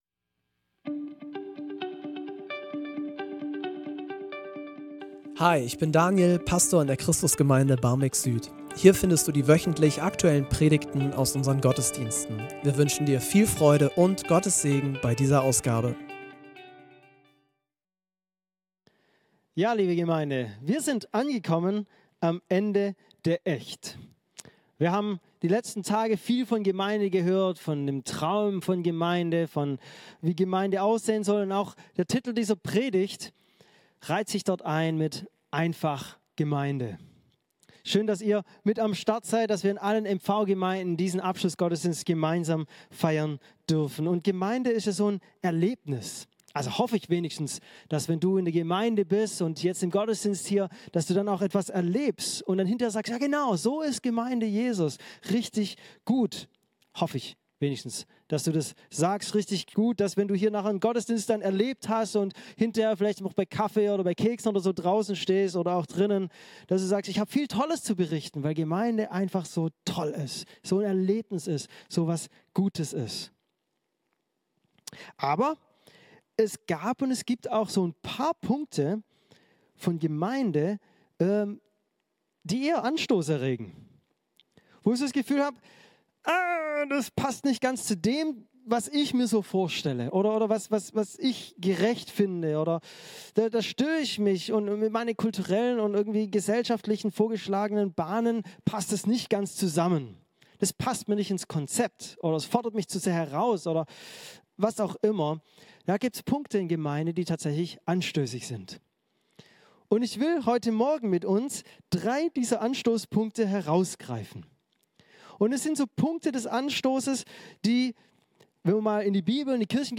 von der ECHT!-Mitarbeiterkonferenz des Mühlheimer Verbandes in Ellmendingen.